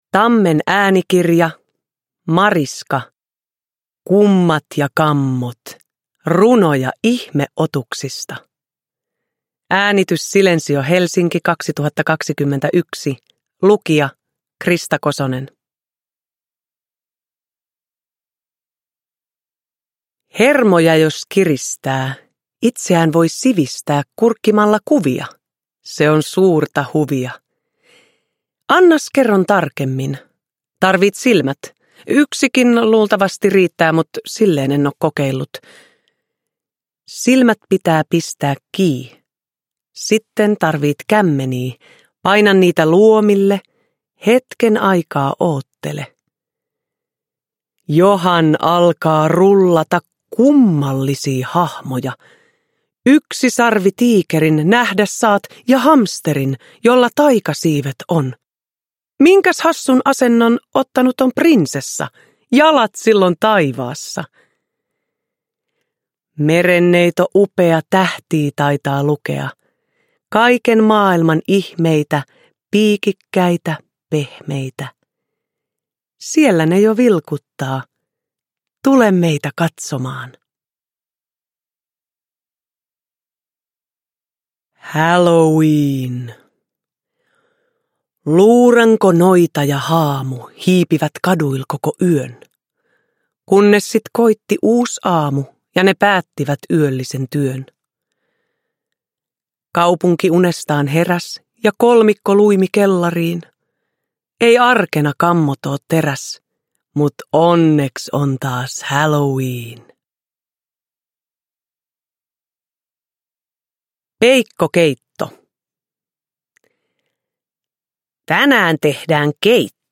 Kummat ja kammot – Ljudbok – Laddas ner
Uppläsare: Krista Kosonen